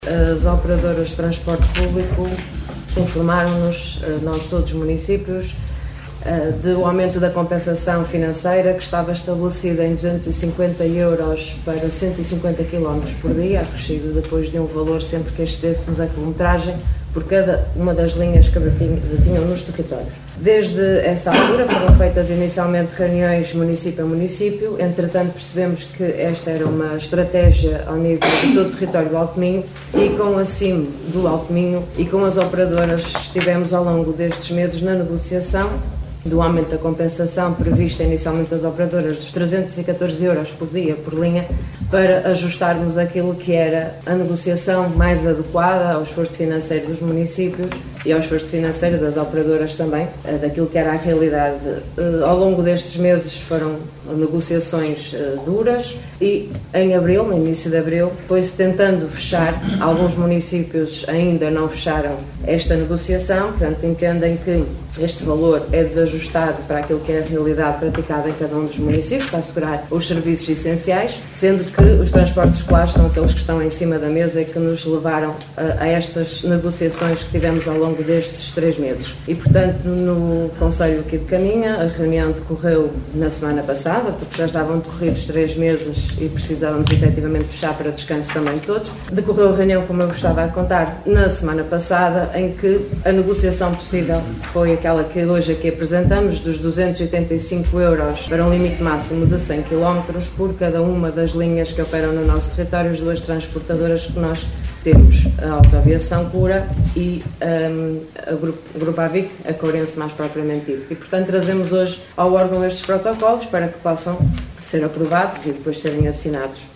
Liliana Silva a justificar o porquê do voto contra desta proposta do executivo socialista.